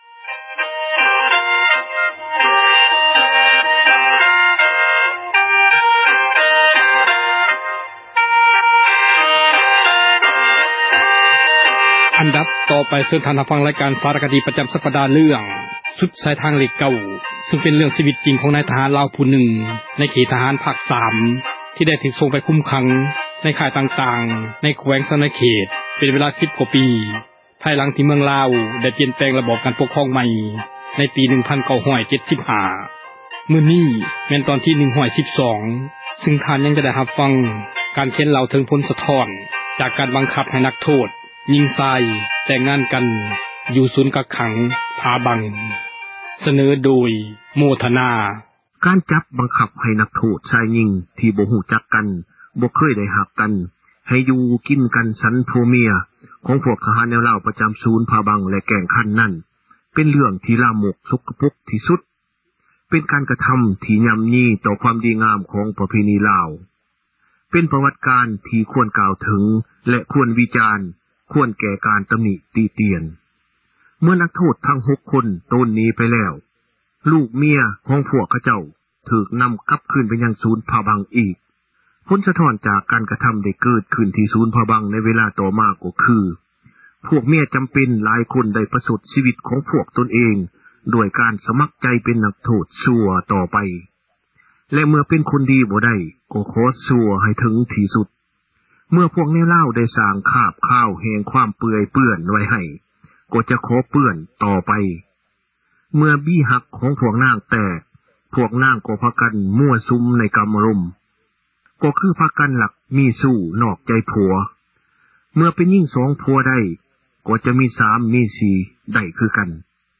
ສາຣະຄະດີ ເຣື້ອງ ສຸດສາຍທາງເລຂ 9 ຕອນທີ 112 ຊຶ່ງ ທ່ານຍັງຈະໄດ້ຮັບຟັງ ການເລົ່າເຖິງ ຜົນສະທ້ອນ ຈາກ ການບັງຄັບ ໃຫ້ນັກໂທດ ຊາຍ-ຍິງ ແຕ່ງງານກັນ ຢູ່ ສູນກັກຂັງ “ຜາບັງ” ສເນີໂດຍ